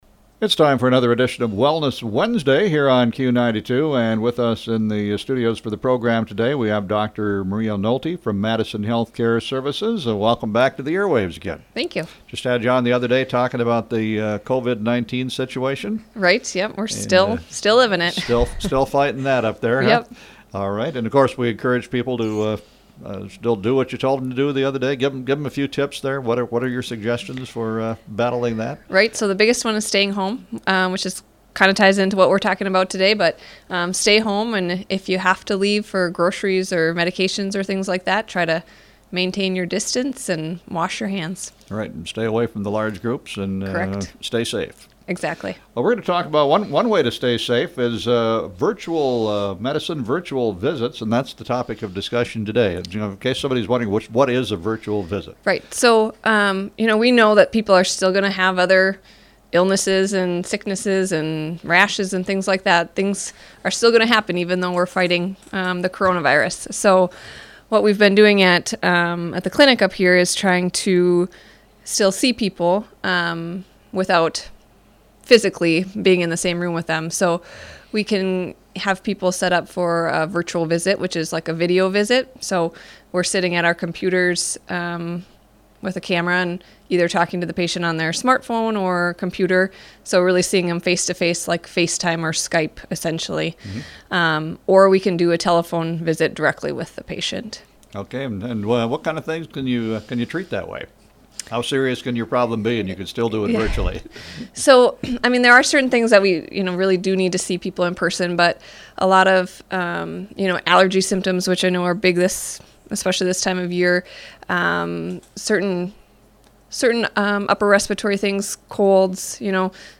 Under: Interviews, Wellness Wednesday